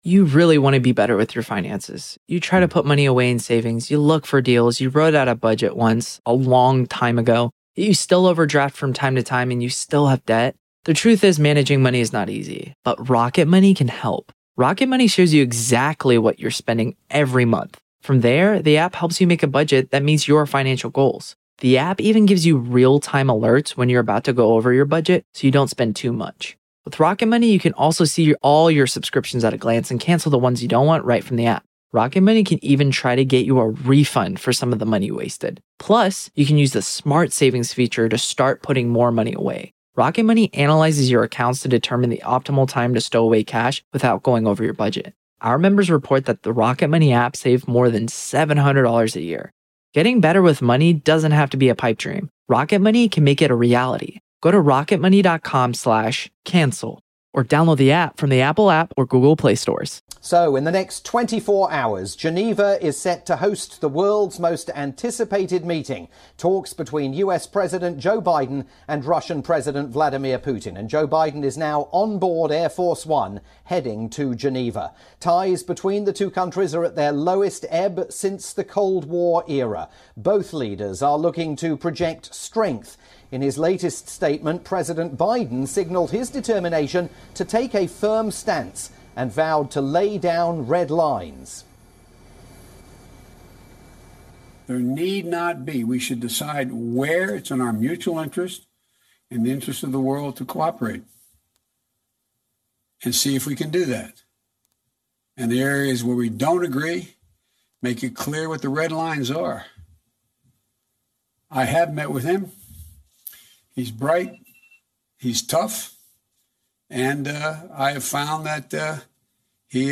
Today including a live report from Moscow on the upcoming Biden/Putin face-off in Geneva.